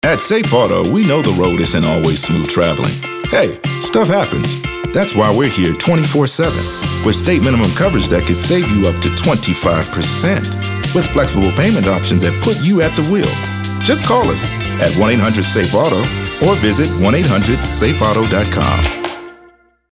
African American, announcer, authoritative, confident, conversational, cool, corporate, Deep Voice, friendly, genuine, guy-next-door, middle-age, midlife, neutral, retail, sincere, thoughtful, warm